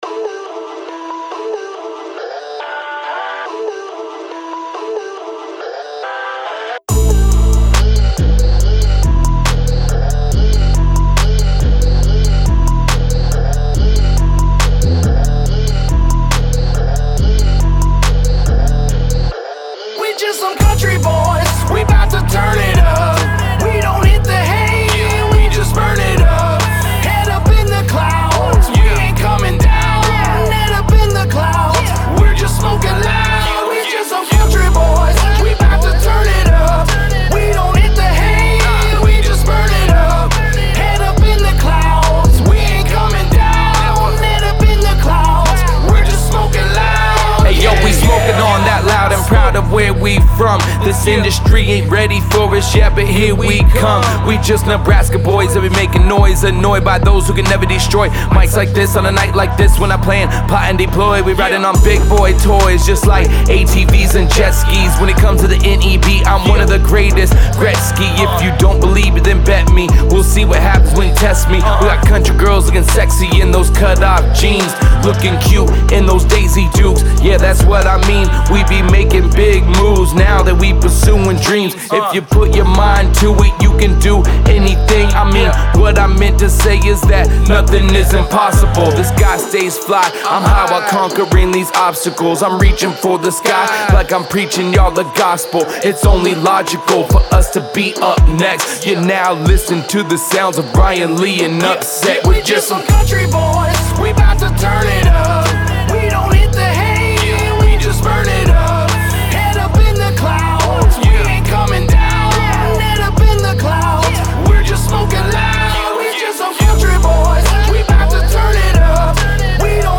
Hiphop
mixing Hiphop and Rock in a display of current sounds